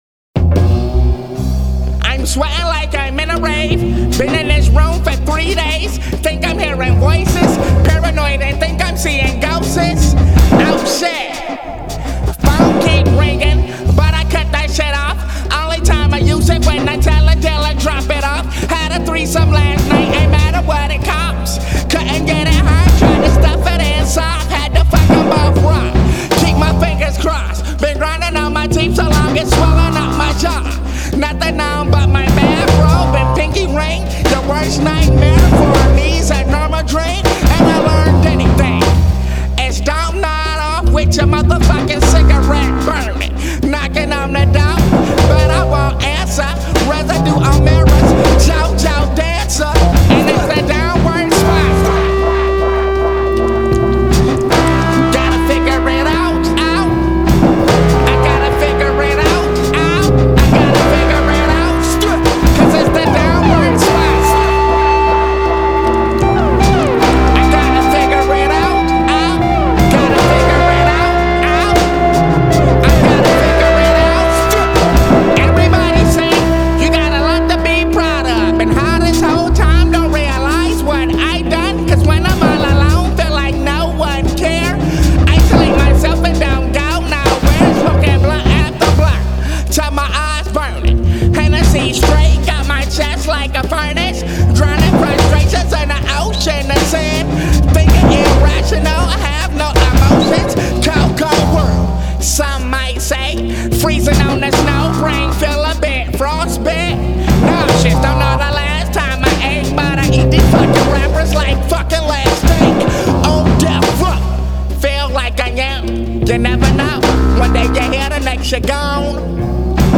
It’s dark, gritty, industrial, and full of wasteland themes.